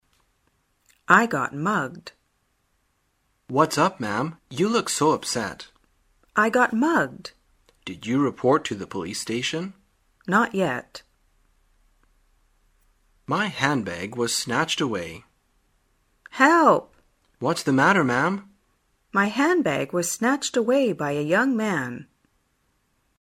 旅游口语情景对话 第333天:如何表达被打劫